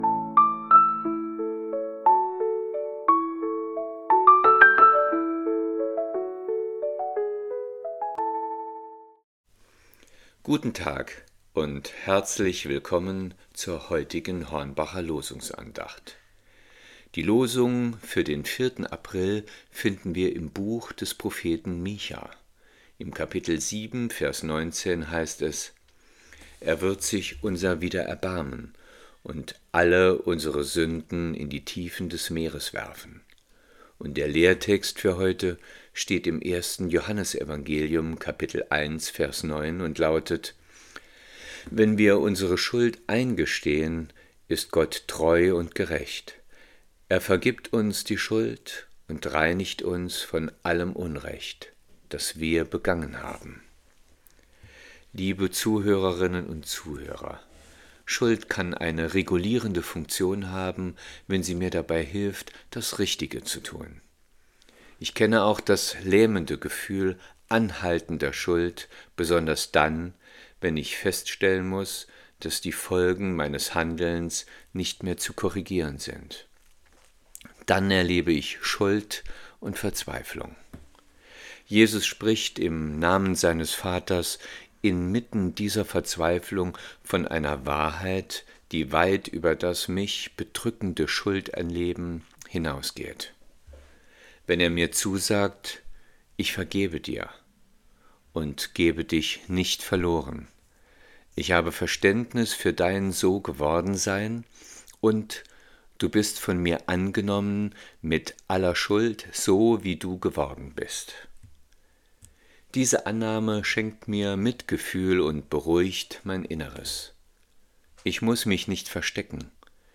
Losungsandacht für Freitag, 04.04.2025